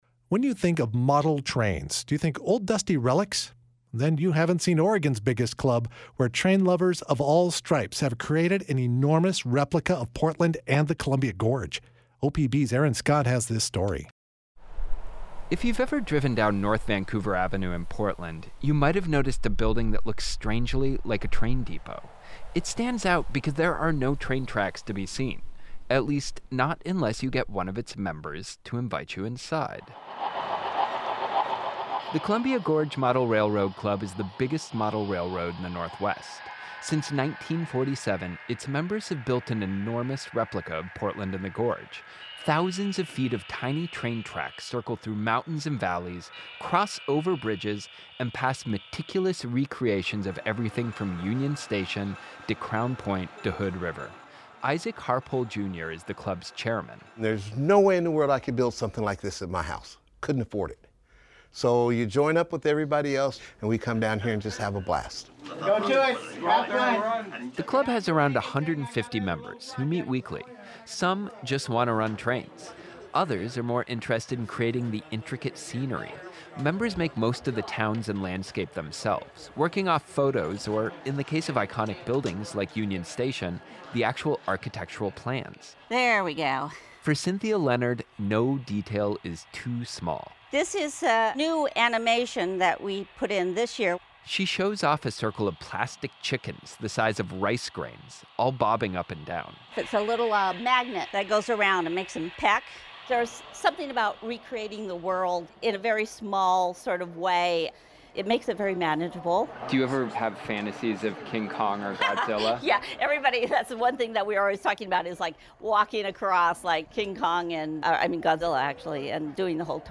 And everywhere, trains are chugging through the landscape, blowing air horns and steam whistles.